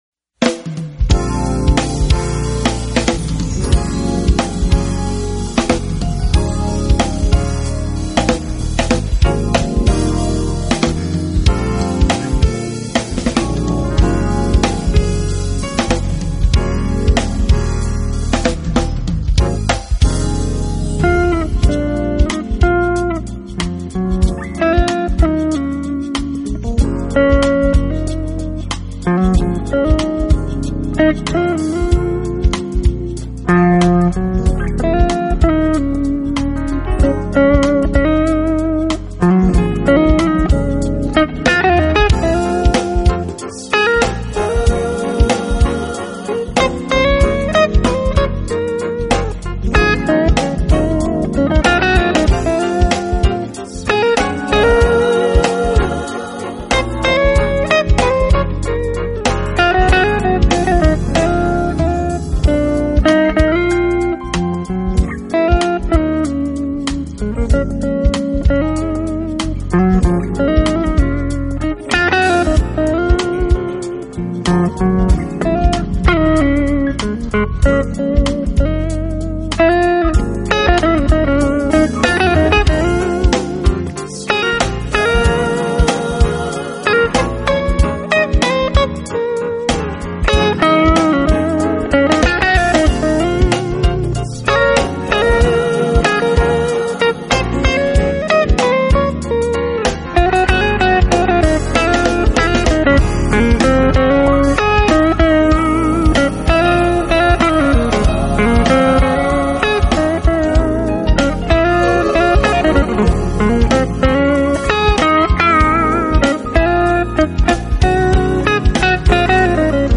爵士乐，乐器的配合很出色，舒缓的曲调很适合休闲时候听一下。